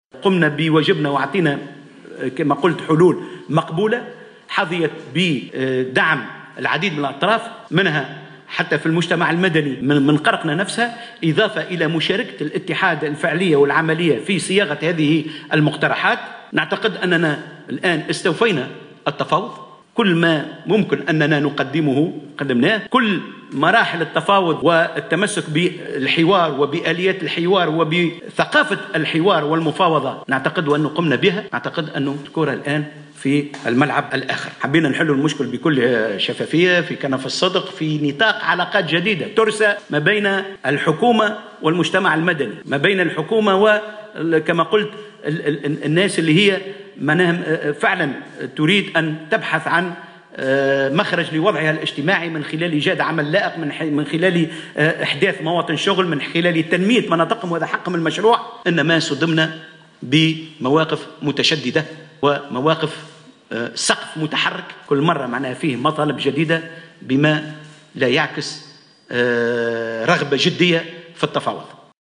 أعلن الناطق الرسمي باسم الحكومة إياد الدهماني خلال نقطة اعلامية عقدت عشية اليوم الأربعاء أن الحكومة استوفت كافة أشكال التفاوض مع شركة بتروفاك قرقنة التي قررت المغادرة.